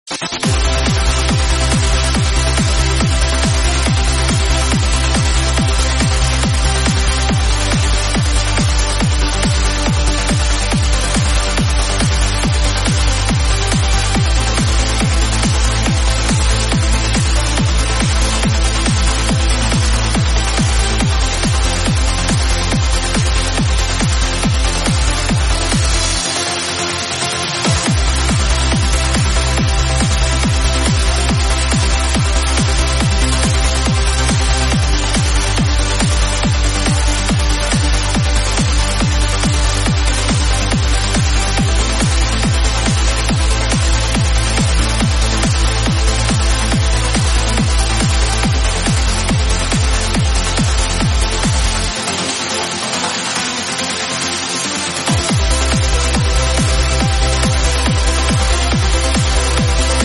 Trance Sets | 138 BPM